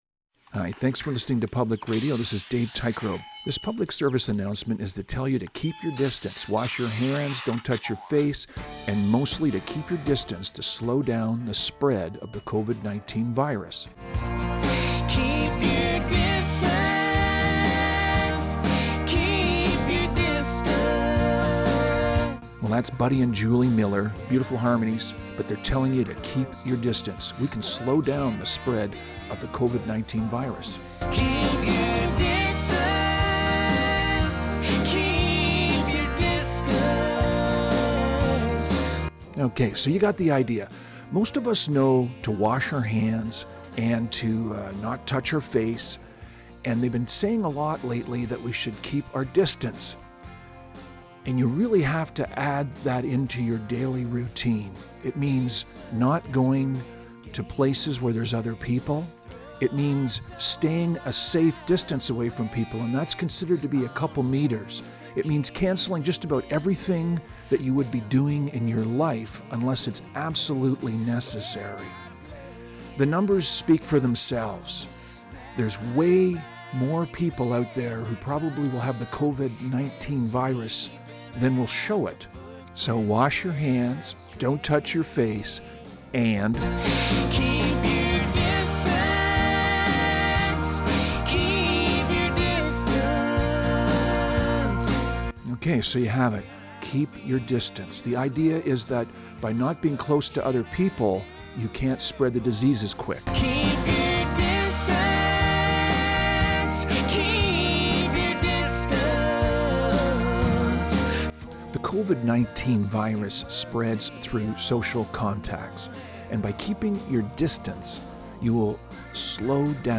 Recording Location: Gabriola Island
Type: PSA